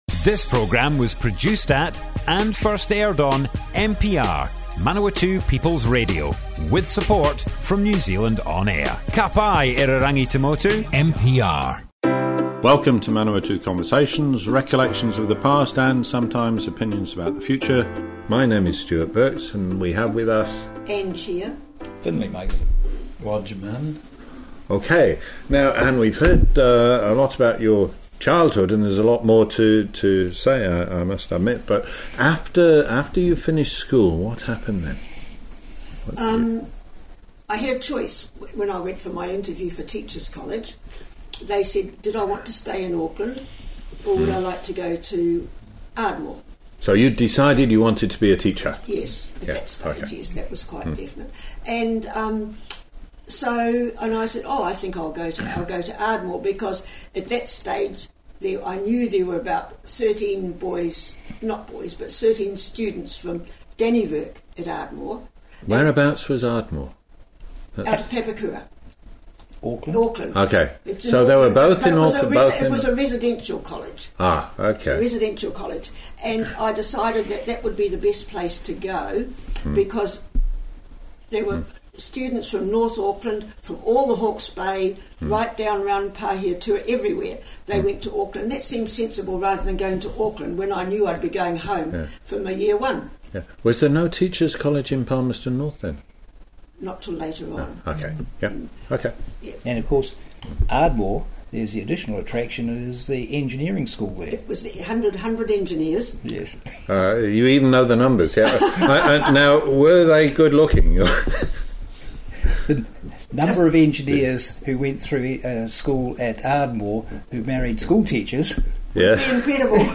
Manawatu Conversations More Info → Description Broadcast on Manawatu People's Radio 24 July 2018.
oral history